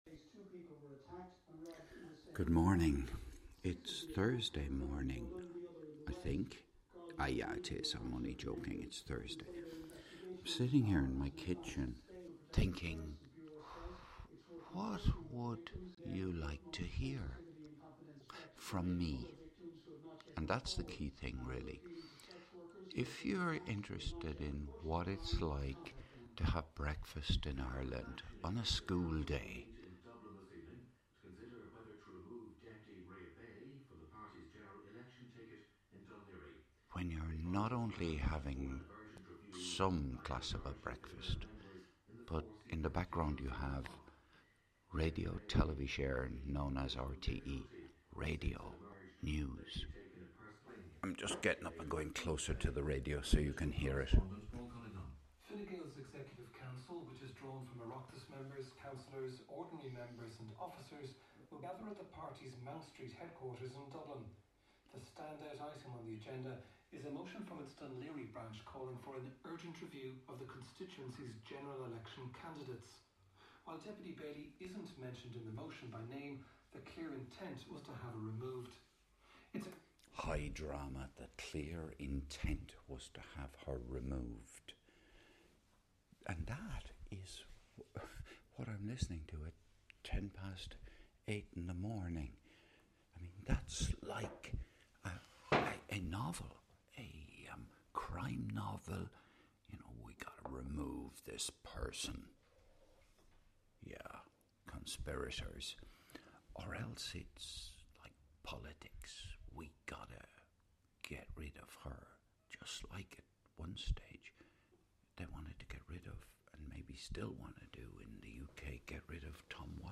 this was recorded on the morning of Thursday 13th of February 2025 - in my kitchen